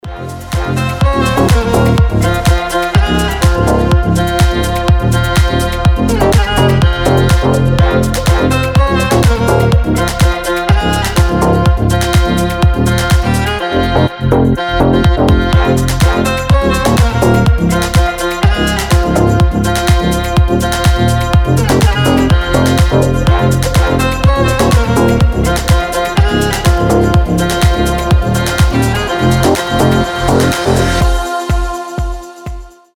• Качество: 320, Stereo
громкие
deep house
восточные мотивы
без слов
красивая мелодия
скрипка